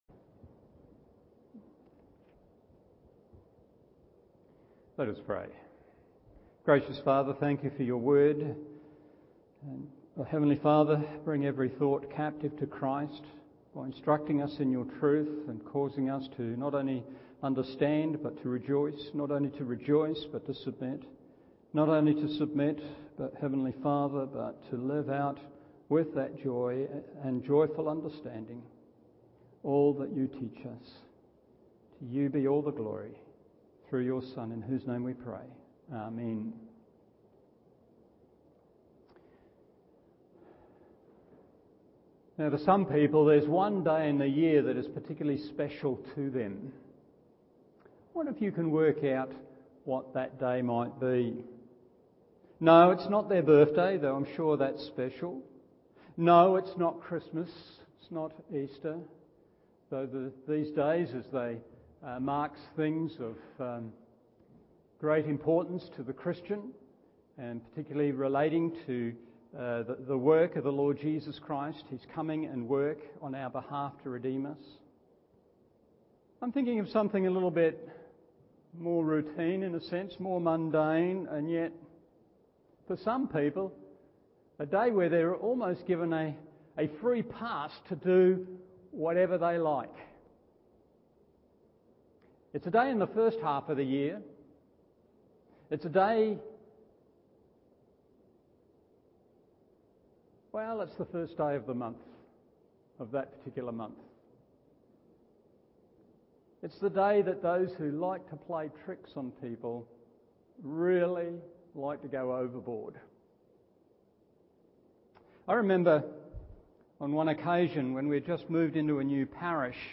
Evening Service 1 Thessalonians 2:1-6 1. Boldness 2. Integrity 3.